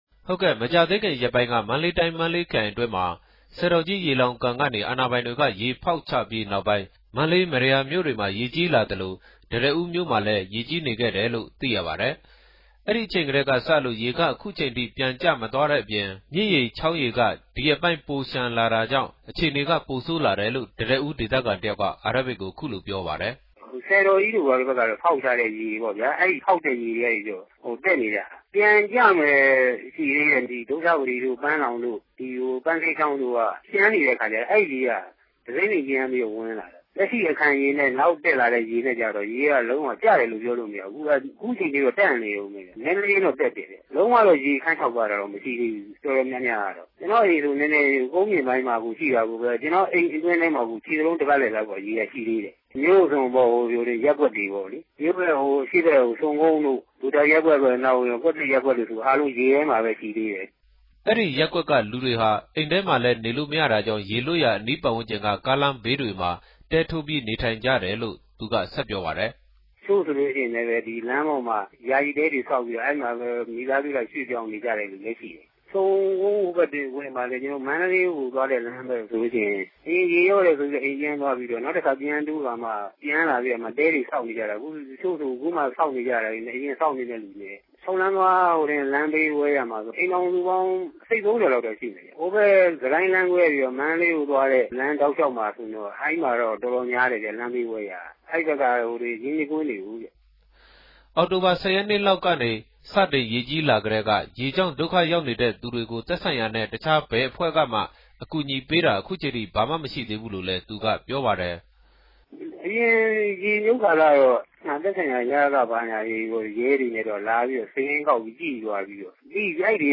သတင်းပေးပို့ချက်။